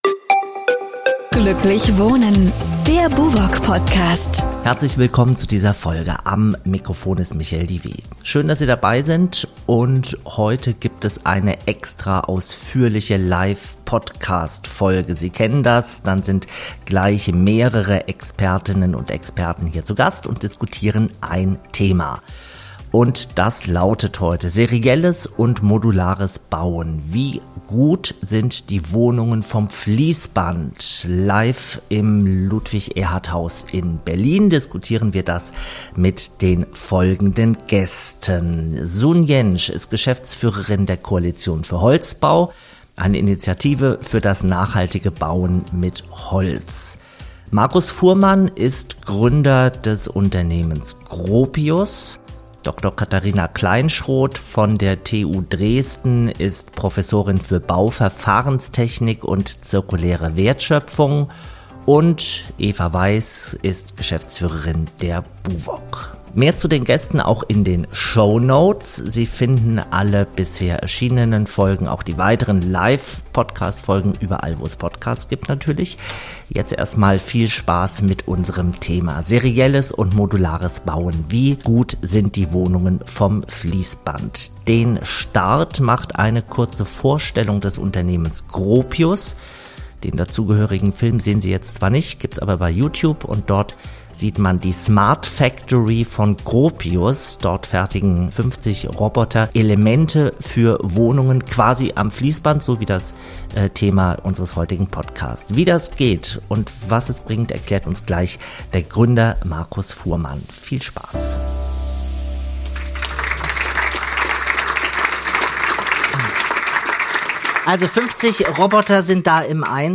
Doch wie gut funktioniert das in der Praxis? In der ersten Berliner Ausgabe von BUWOG im Gespräch diskutieren hochkarätige Expert:innen über die Chancen und Herausforderungen dieser Bauweise.